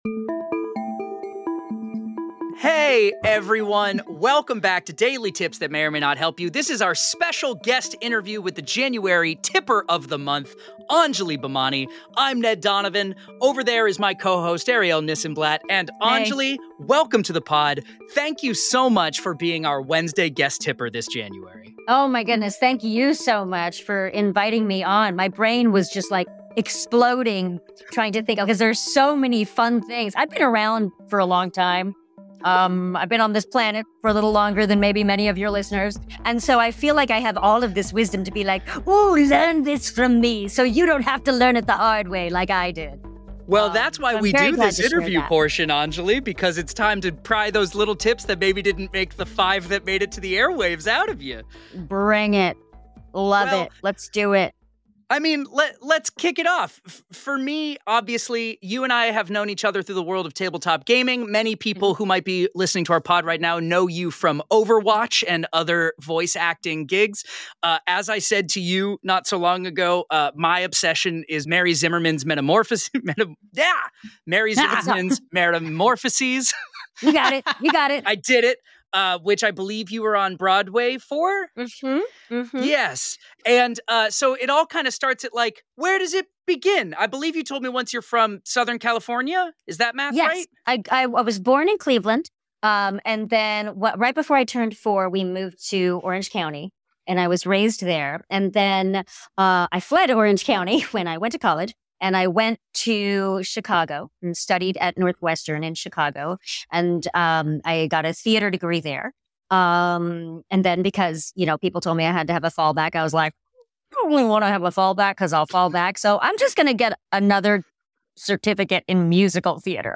Join us for this inspiring conversation about creativity, resilience, and finding joy in both your professional and personal life.